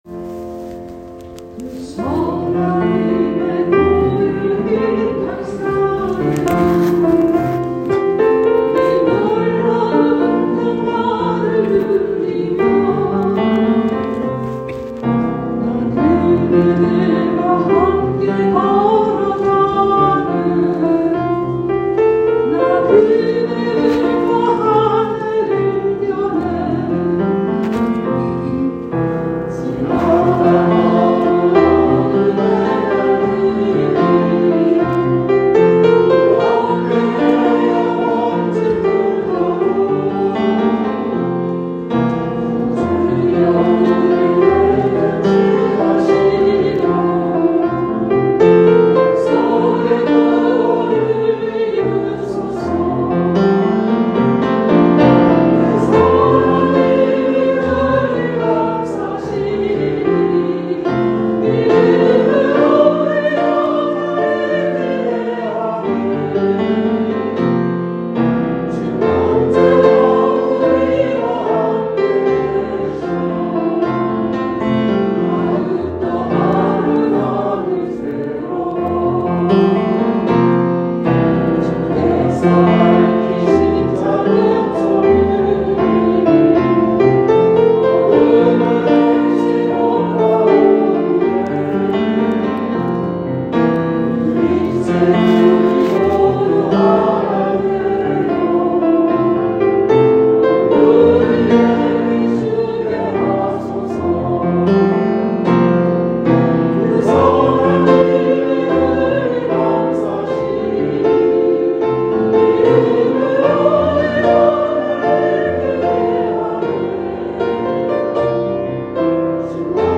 Rejoice & Jublilee Choir
2022년 1월 2일 신년주일 쥬빌리 찬양대